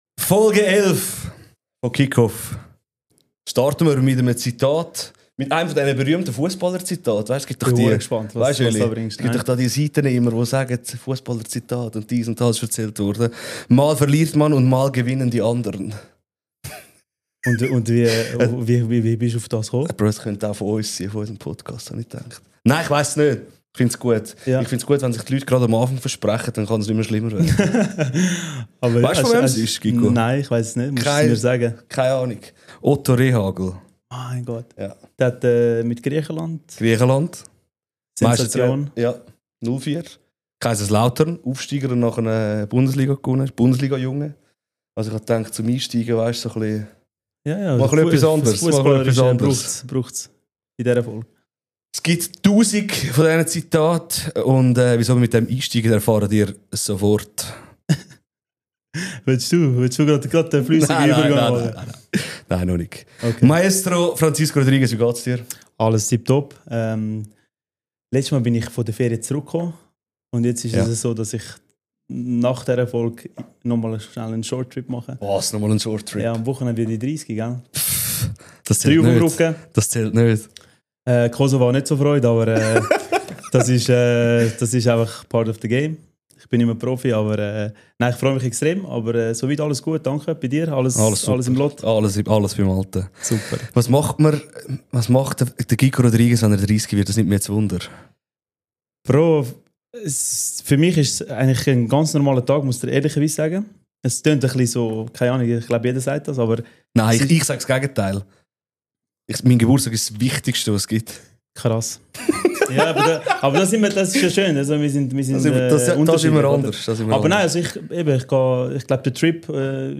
Mit seinem Basler Dialekt, seiner Leidenschaft für den schönsten Sport der Welt und unzähligen Geschichten nimmt er uns mit hinter die Kulissen des Sportjournalismus. Wie fühlt es sich an, den Traum vieler Sportjournalisten zu leben? Welche Seiten des Geschäfts sieht man nur abseits der Kameras?